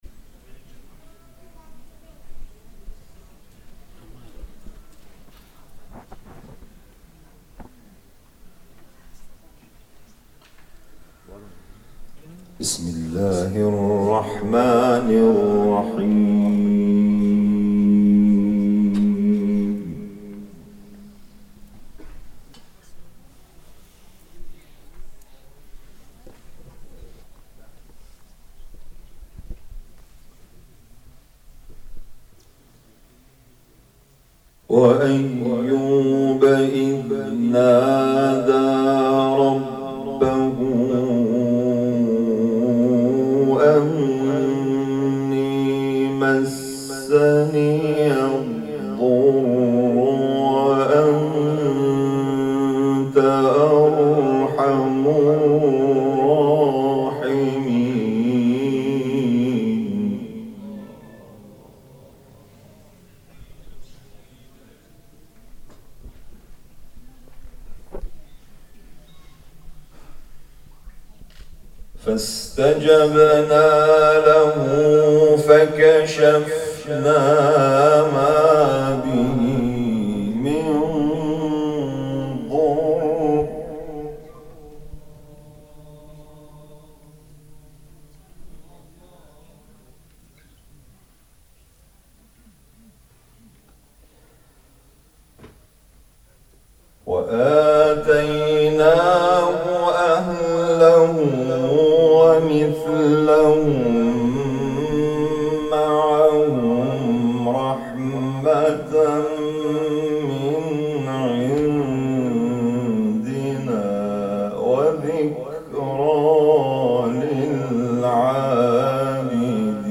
تلاوت مجلسی از سوره‌های مبارکه انبیا و کوثر